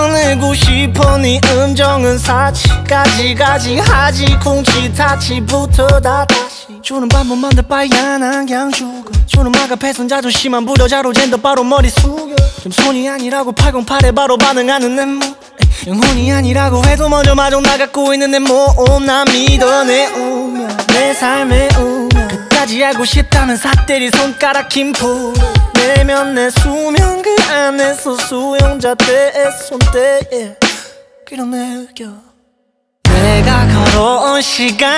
Жанр: Поп музыка / R&B / Соул